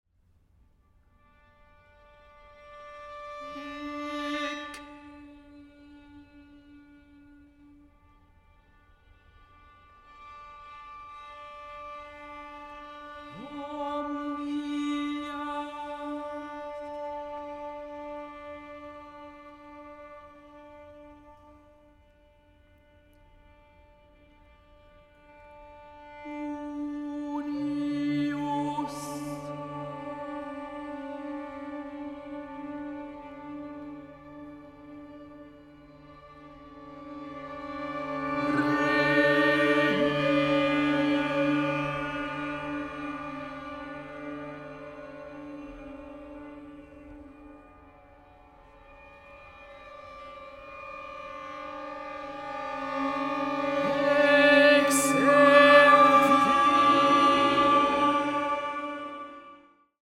Auch in Dolby Atmos
Vokalensemble
Aufnahme: University of Bergen, Norway, 2024